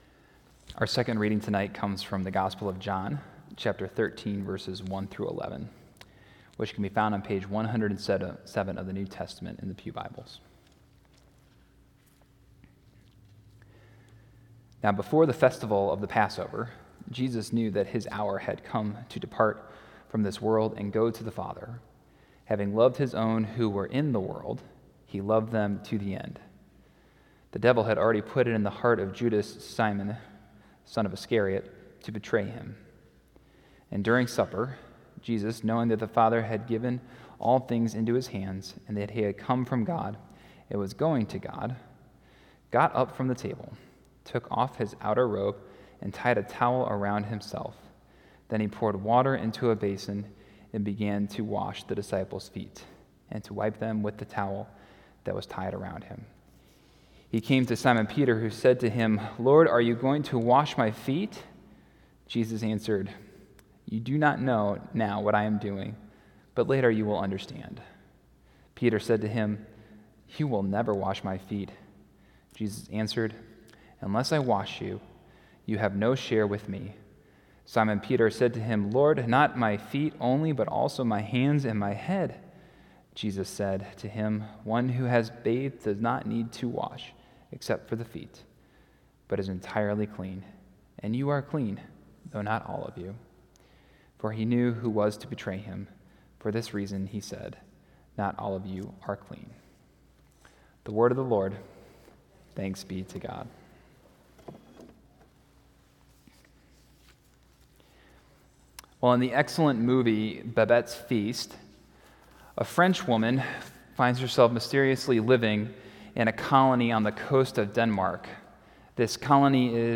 Maundy Thursday 2019